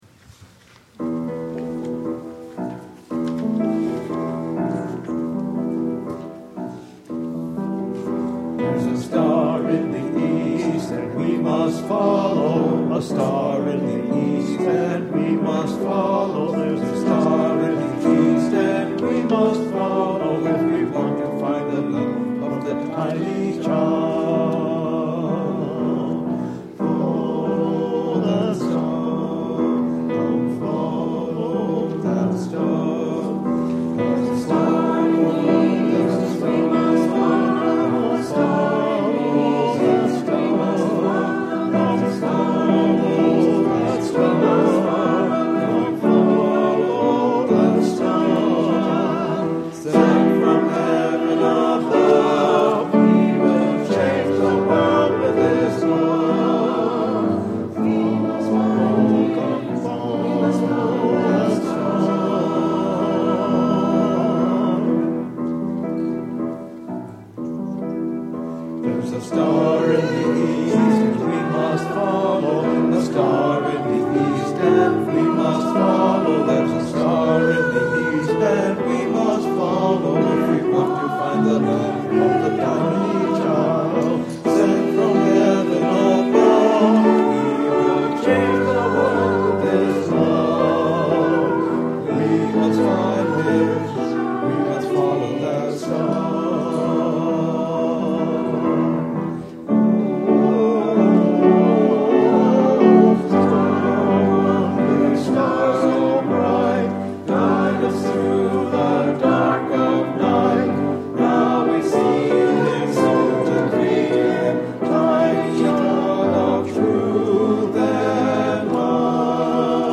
15 Choir Anthem during Offering Collection.mp3